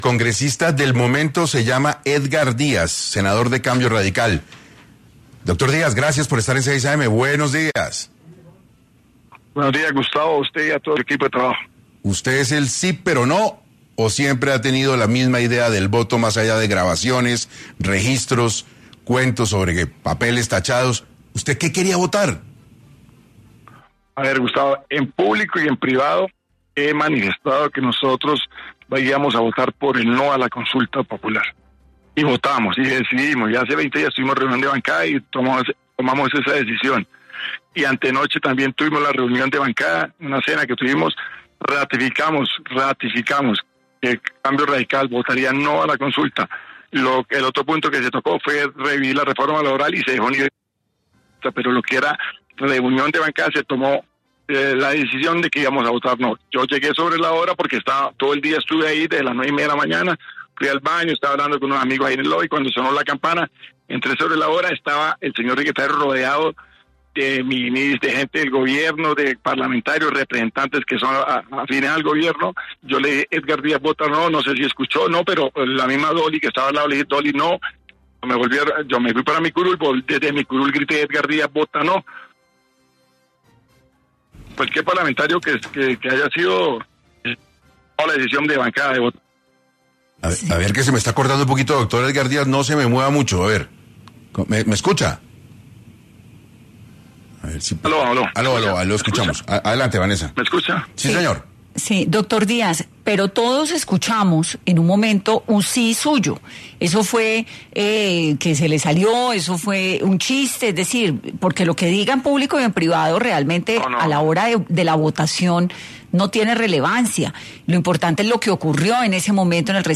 En entrevista para 6AM, el mismo Senador, aclaró la situación, y explicó por qué muchos confundieron su respuesta.